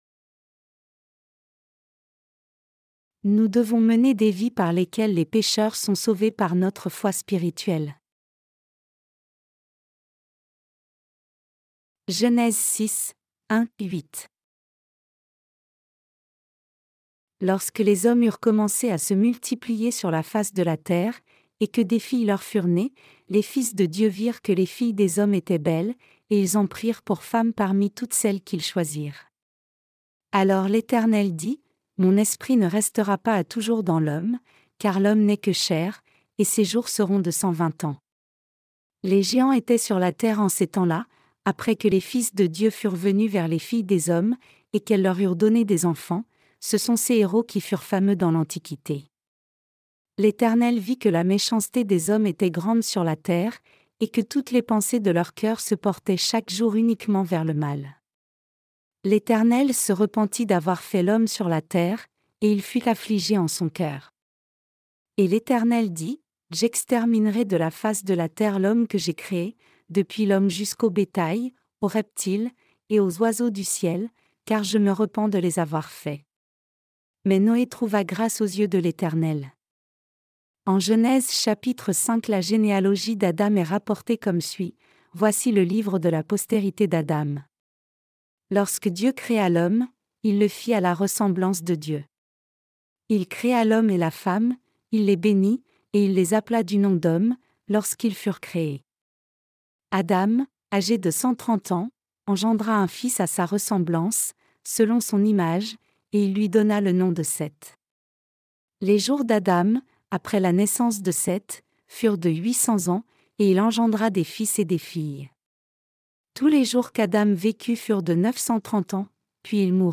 Sermons sur la Genèse (V) - LA DIFFERENCE ENTRE LA FOI D’ABEL ET LA FOI DE CAÏN 13.